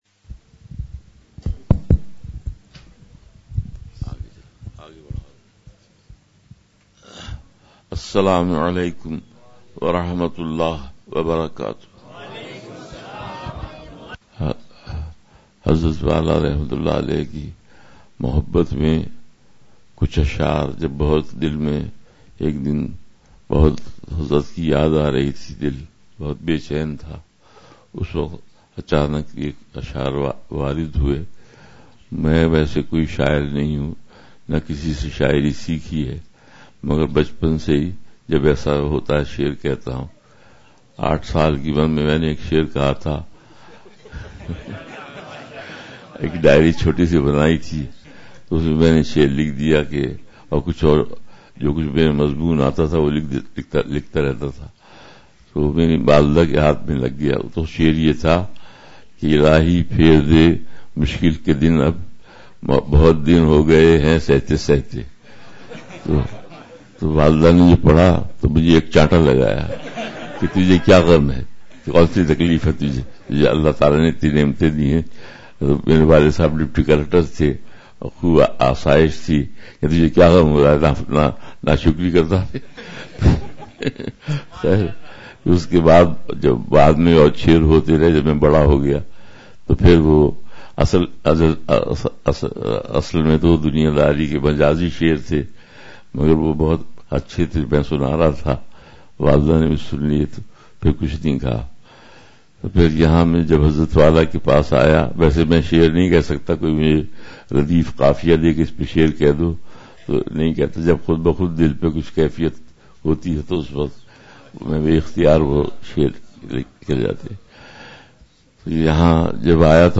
آج کی مجلس بہت پُر کیف تھی ،